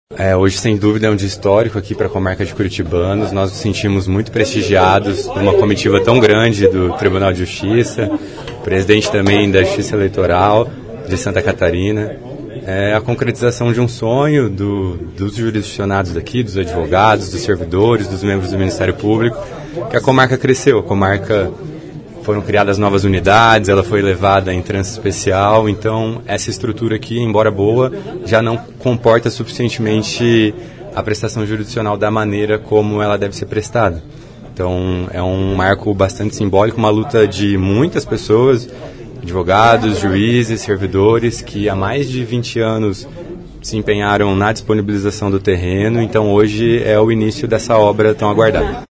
O juiz diretor do foro, Rafael Resende Britto, ressaltou que a ampliação e qualificação dos espaços contribuirão diretamente para a melhoria dos serviços prestados.